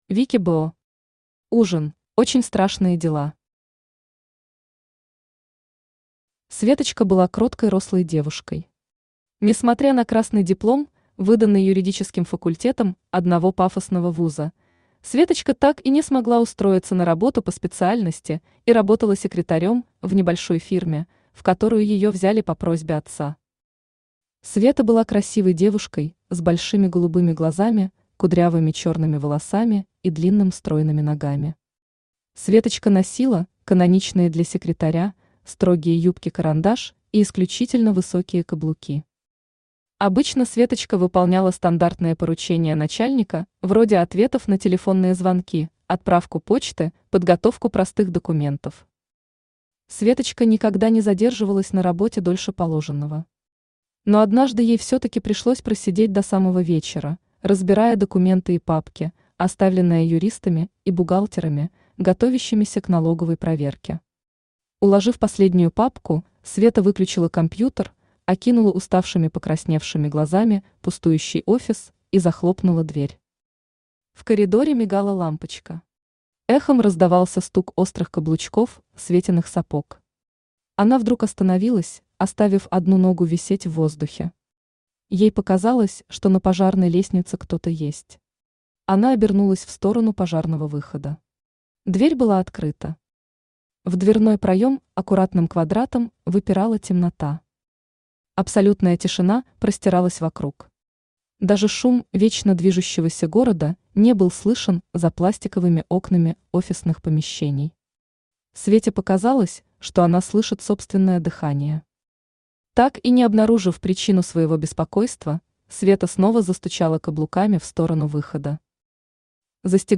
Аудиокнига Ужин | Библиотека аудиокниг
Читает аудиокнигу Авточтец ЛитРес.